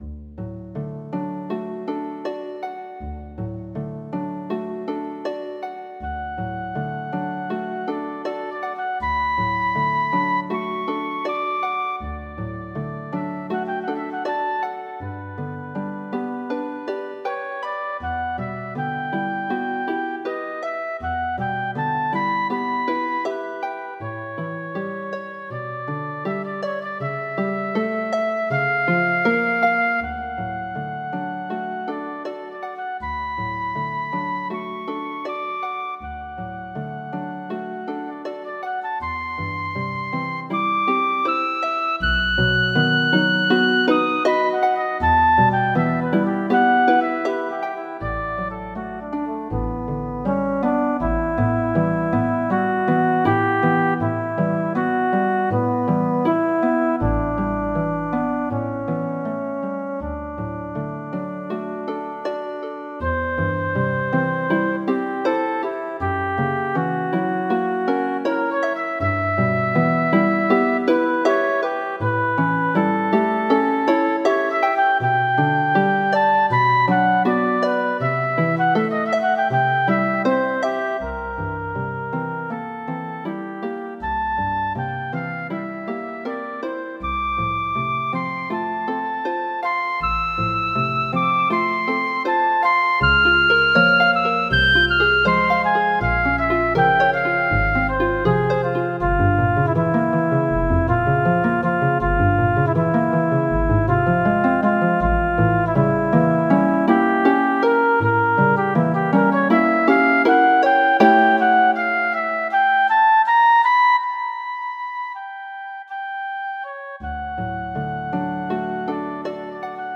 for pedal harp and flute, or any treble instrument